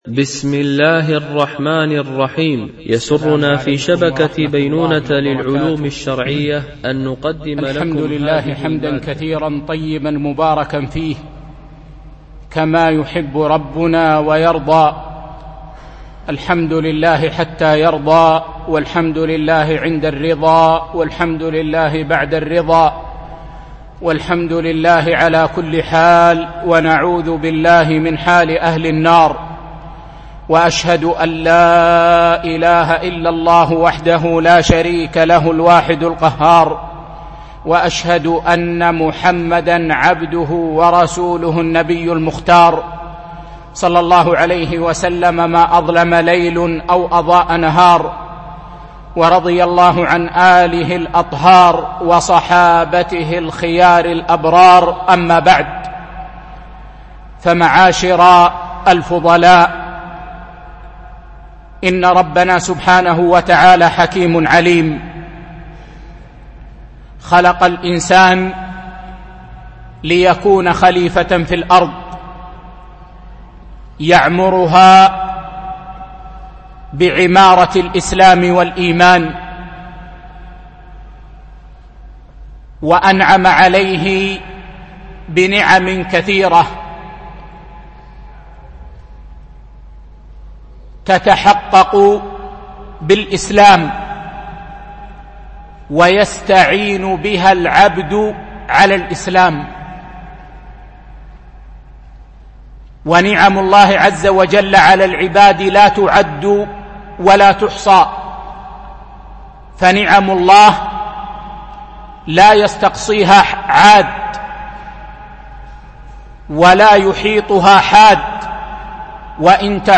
محاضرة: دور المواطن والمقيم في تعزيز الأمن